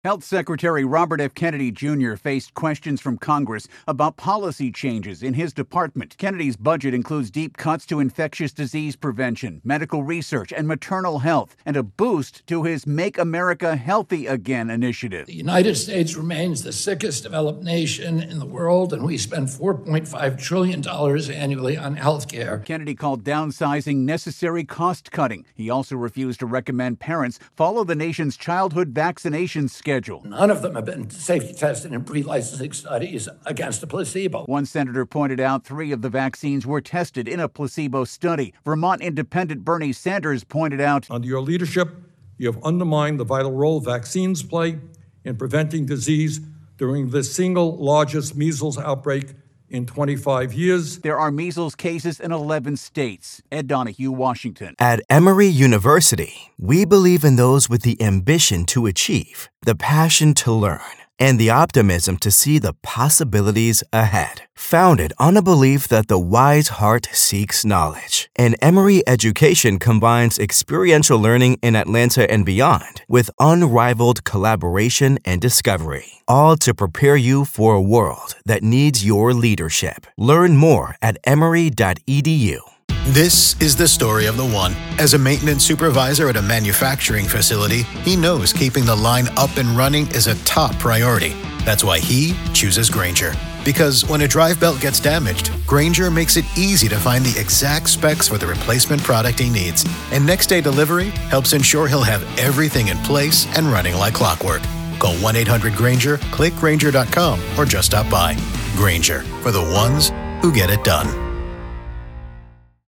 reports on the healthy secretary's budget defense before Congress.